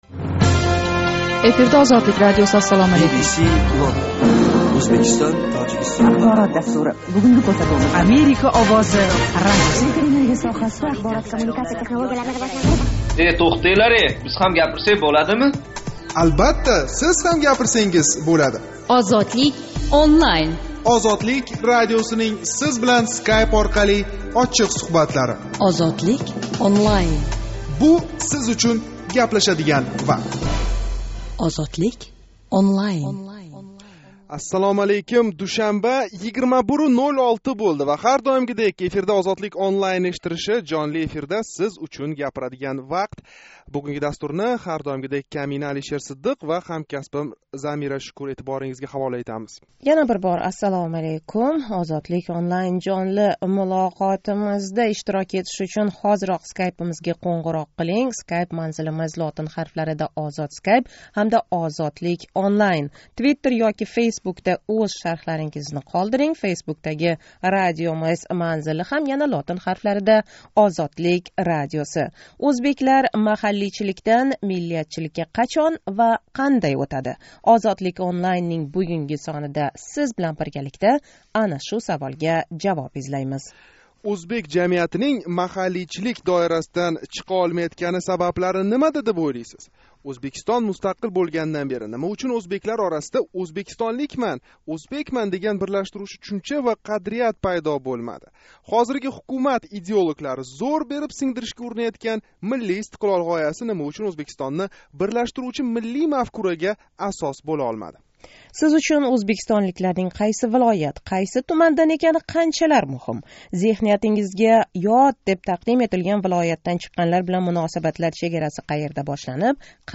Душанба¸ 26 ноябр куни Тошкент вақти билан 21:05 да бошланган жонли¸ интерактив мулоқотимизда ўзбеклар миллий бирлигига энг катта тўсиқ деб кўрилаëтган маҳаллийчилик илдизлари ва ундан озод бўлиш имконлари ҳақида гаплашдик.